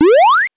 8 bits Elements
powerup_36.wav